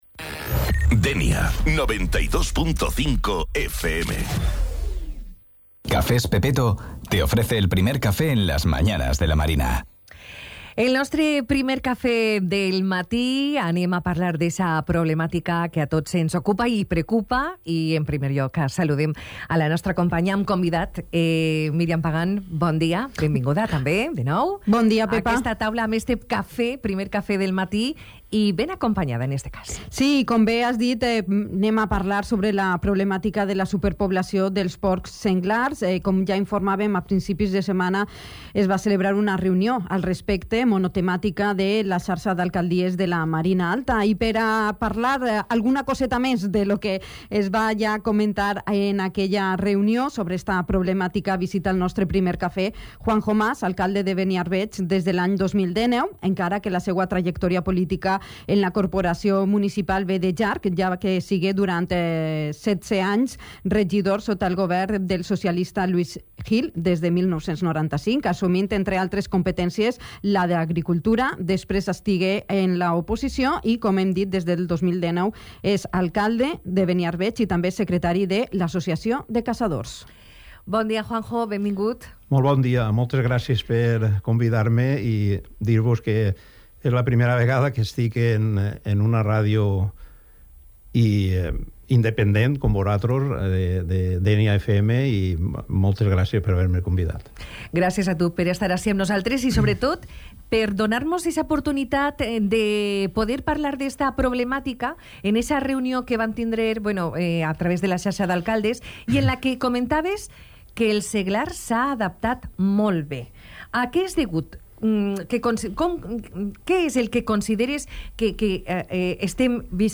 Así lo ha asegurado el alcalde de Beniarbeig, Juanjo Mas (además secretario de l’Associació de Caçadors), en la entrevista en el ‘primer café’ de Dénia FM, en el que se ha abordo la problemática de la superpoblación de jabalíes en la Marina Alta.
Entrevista-Juanjo-Mas-alcalde-Beniarbeig.mp3